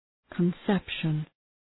Προφορά
{kən’sepʃən}
conception.mp3